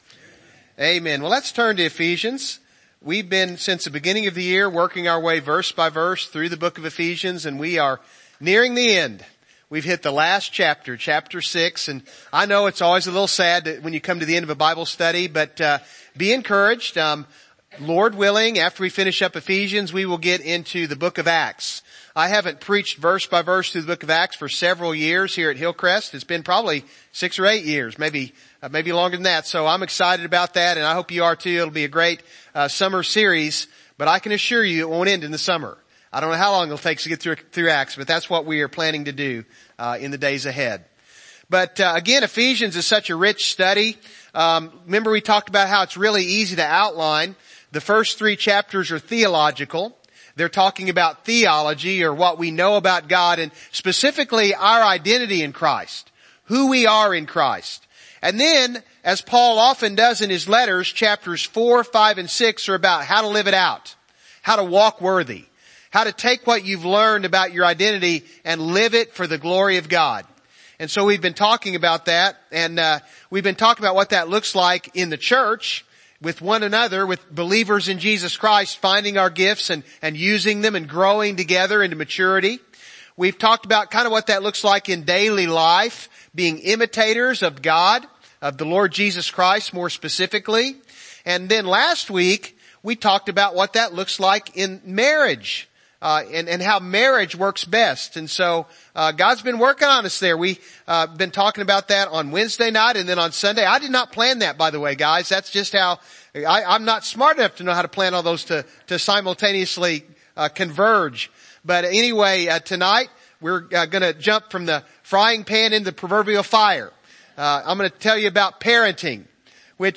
Service Type: Evening Service